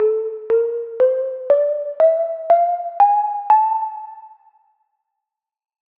Neapolitan min.
2025-kpop-scale-nea.mp3